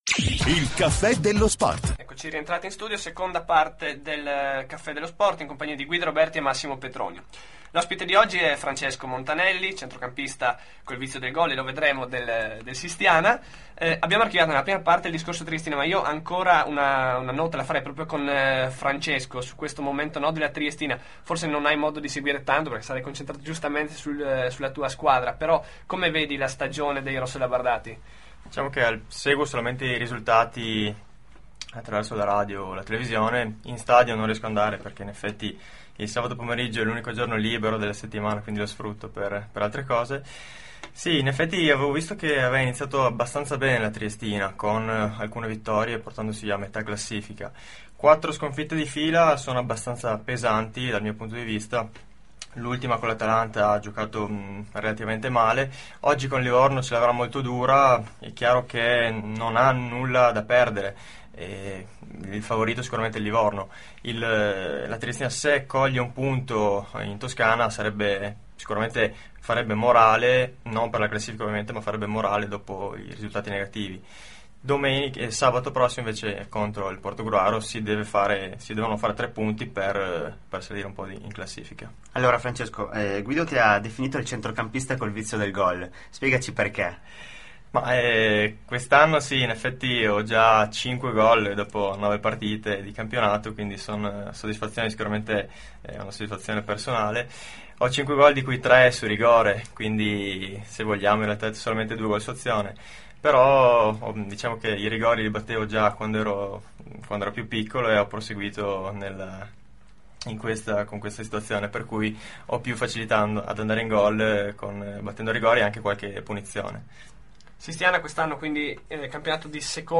INTERVISTA A RADIOATTIVITA'